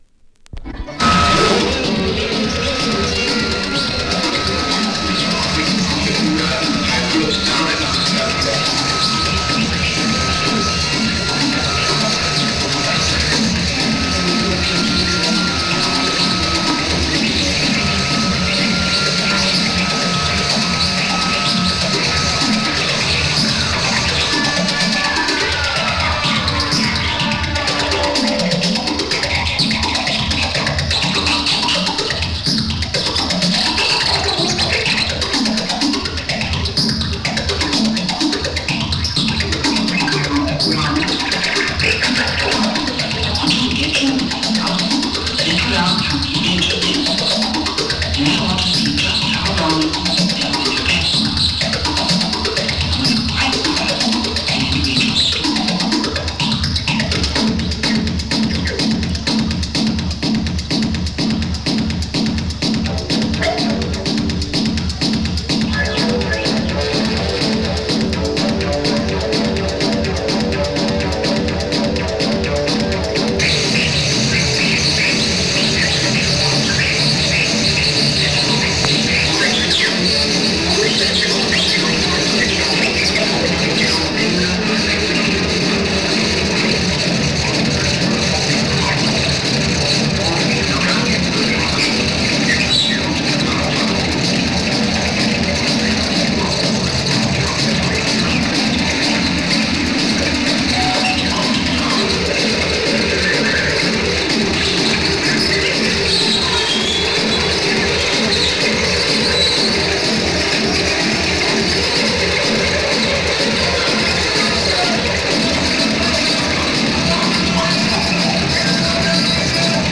A1 Live West Runton Pavilion 6 / 3 / 79